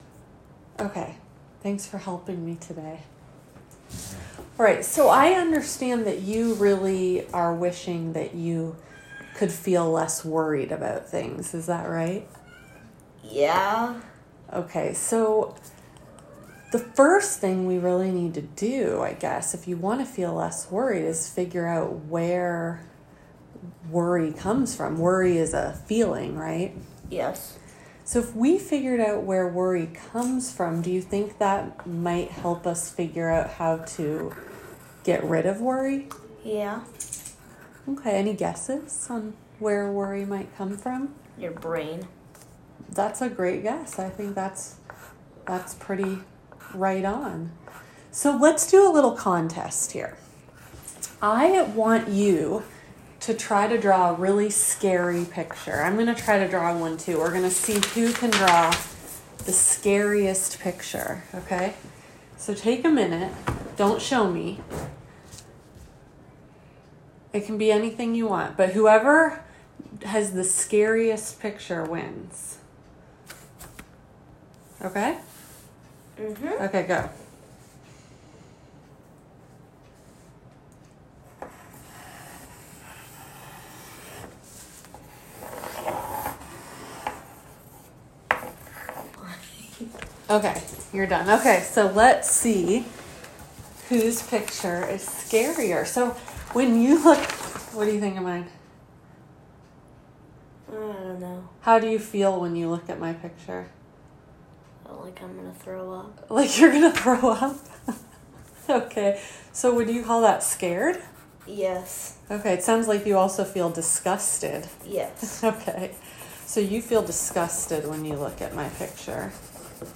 Below you will find audio recordings of sample techniques for various Exposure-Based CBT sessions with kids.
Child Therapist Demo: Where Do Emotions Come From?